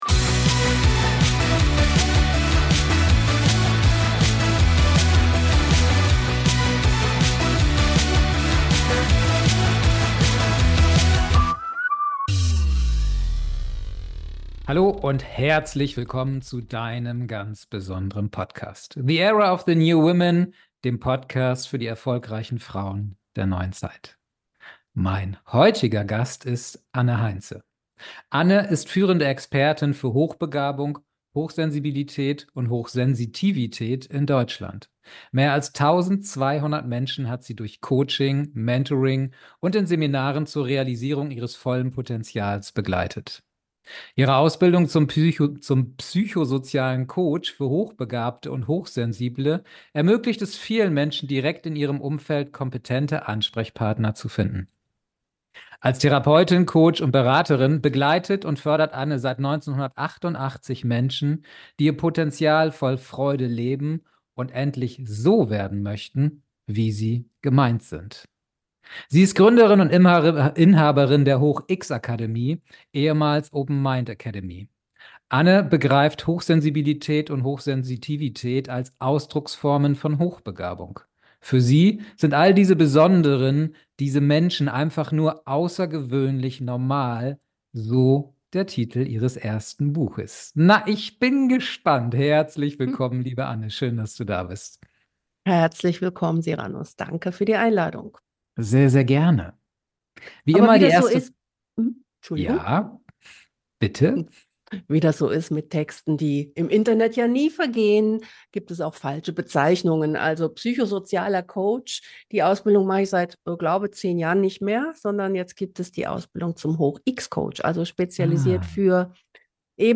#039 Was wahre Freiheit in der Tiefe bedeutet. Das Interview